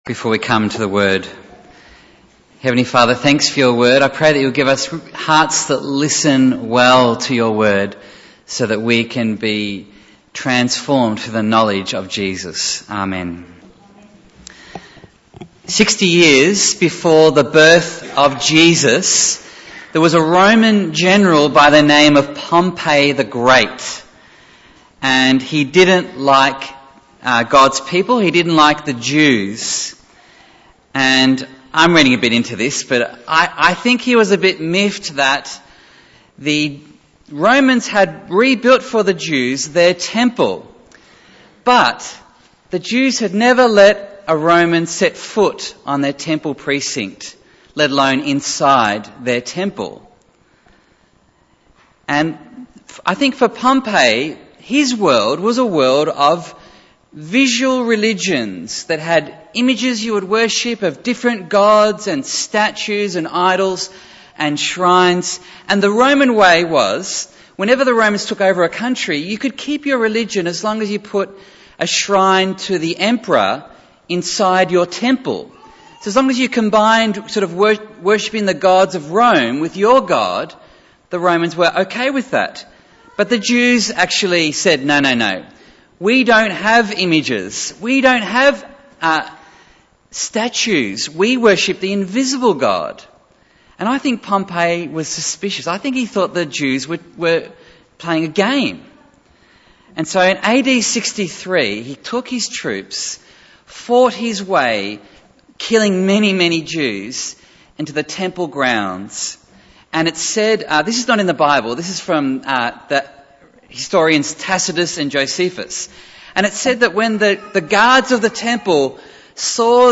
Bible Text: Joshua 24:14-27 | Preacher